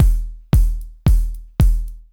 DWS KIK HH-R.wav